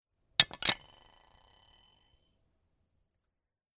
Glass
Put on the lid
3517_Deckel_aufsetzen.mp3